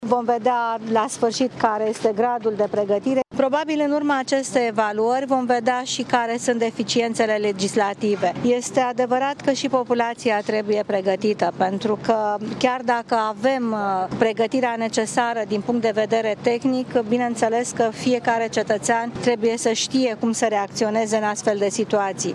Declarația Vioricăi Dăncilă (audio)
Simularea unui cutremur va permite identificarea eventualelor deficienţe organizatorice şi legislative, dar este util şi pentru ca populaţia să ştie ce are de făcut în caz de cutremur, a declarat premierul Viorica Dăncilă, care a fost, ieri, la Academia de Poliţie, unul dintre locurile din Bucureşti care fac parte din scenariul exerciţiului: